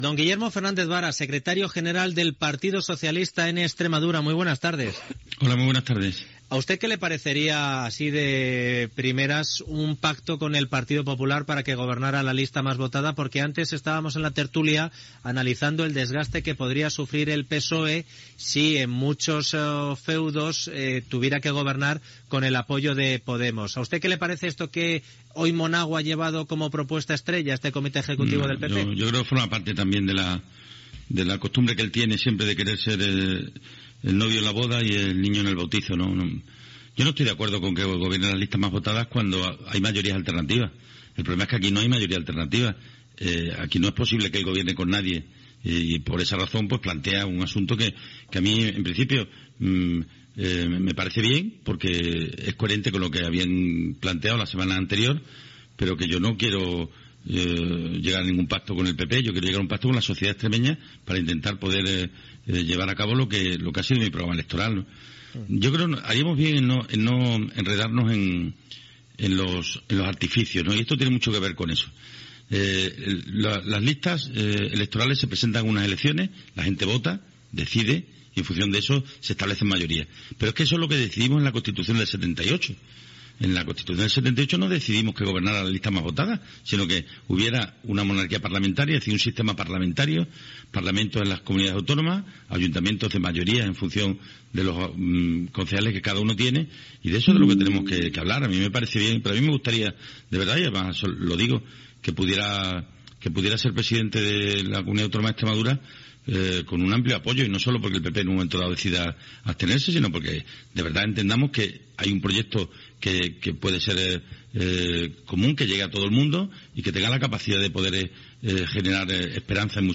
Fragment d'una entrevista al polític extremeny Guillermo Fernández Vara.
Info-entreteniment